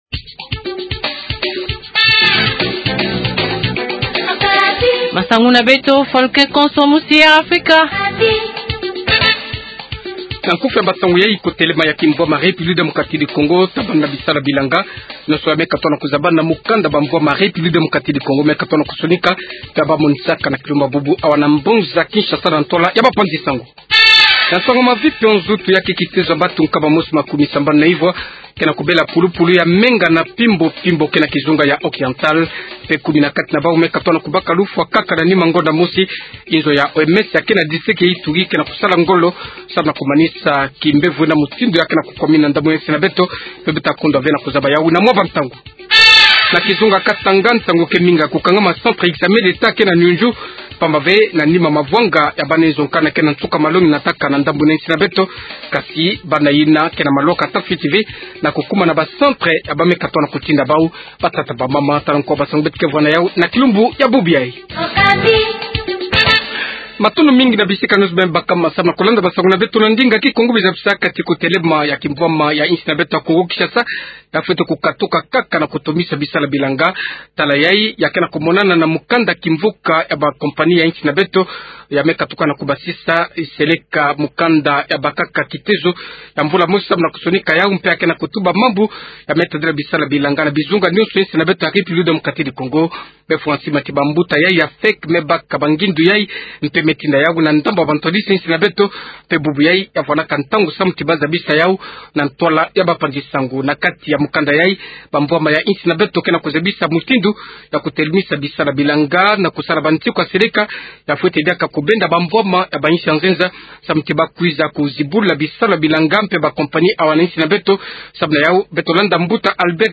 100507-journal kikongo soir